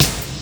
• Eighties Trebly Reggae Acoustic Snare C# Key 75.wav
Royality free acoustic snare sound tuned to the C# note. Loudest frequency: 4084Hz
eighties-trebly-reggae-acoustic-snare-c-sharp-key-75-ZZs.wav